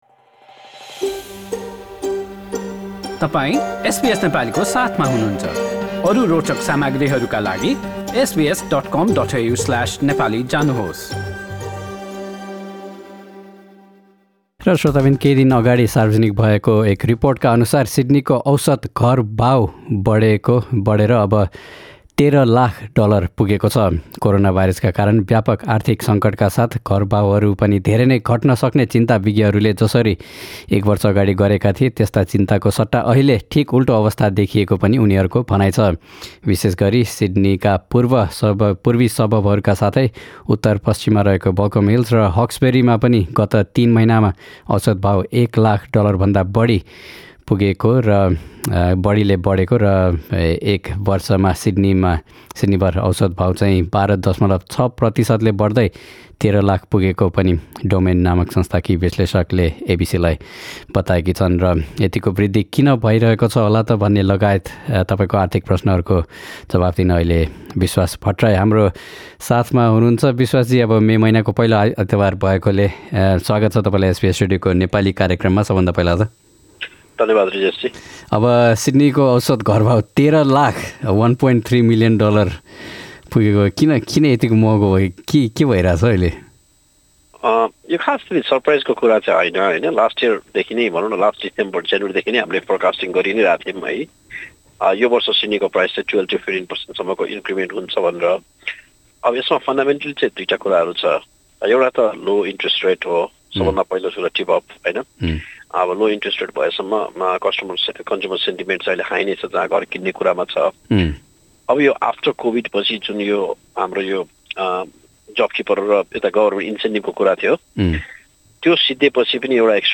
आर्थिक कुराकानी हरेक महिनाको पहिलो आइतवार अपराह्न ४ बजेको कार्यक्रममा प्रत्यक्ष प्रसारण हुन्छ।